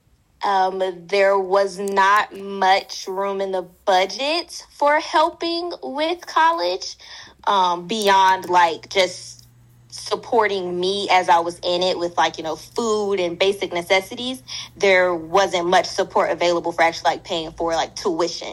Demographics: Black woman, 24 (Generation Z)[23]